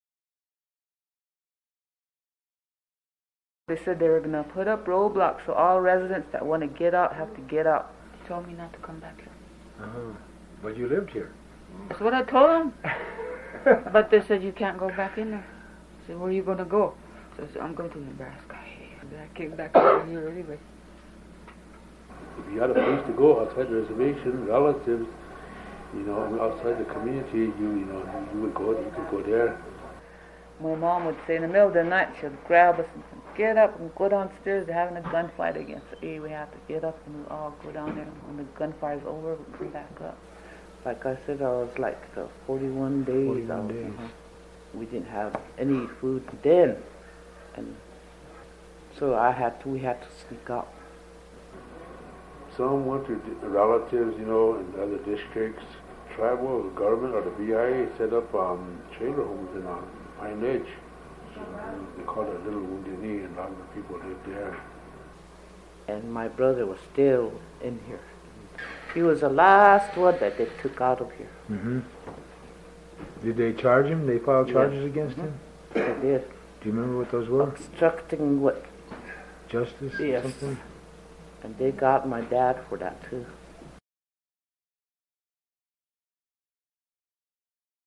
These three radio reports provide new insights on the occupation
Residents share their memories of their experiences during the occupation.